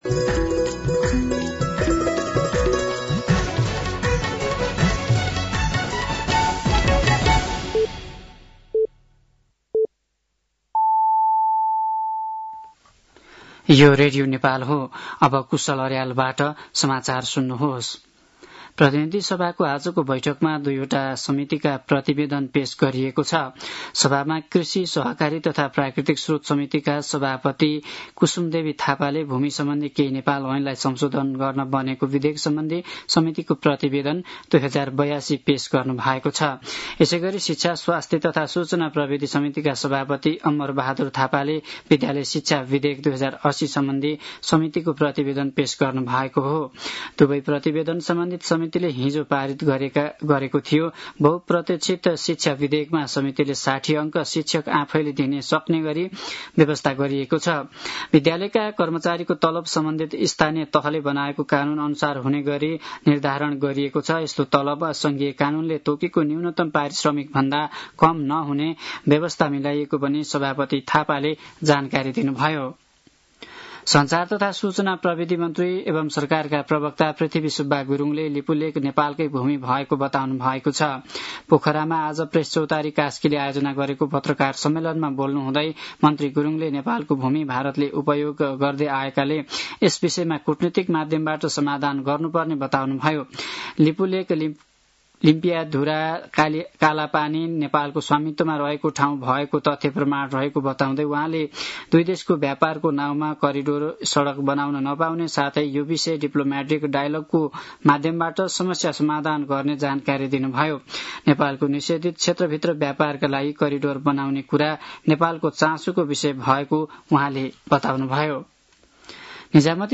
साँझ ५ बजेको नेपाली समाचार : ६ भदौ , २०८२